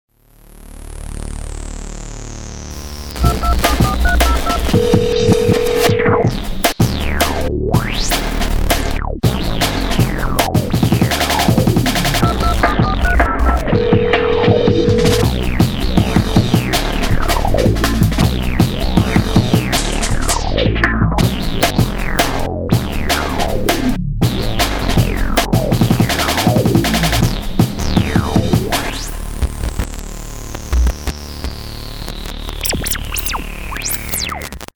Thème d'émission de télévision